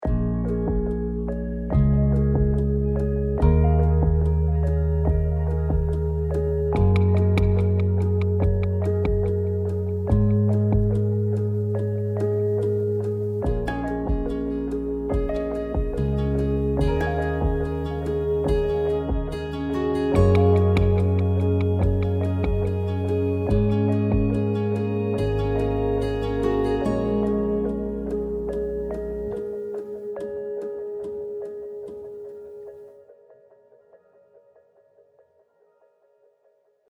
ohne Gitarre